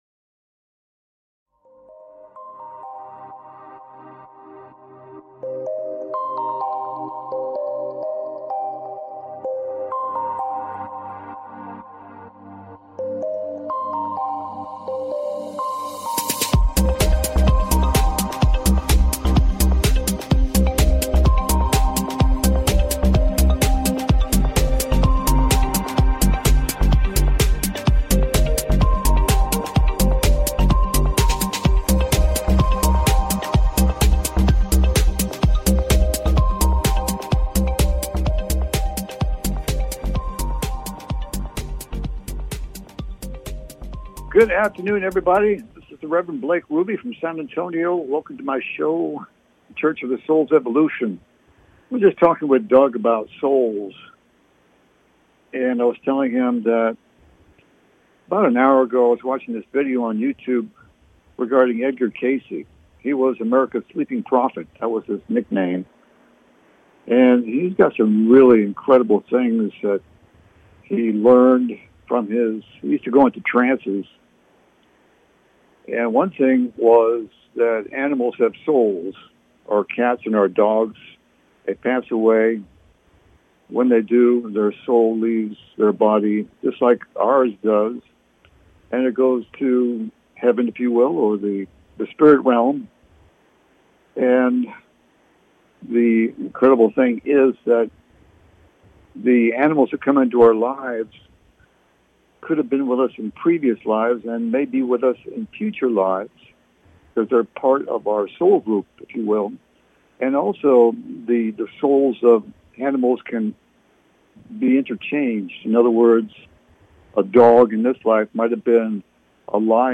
The Church of the Souls Evolution Talk Show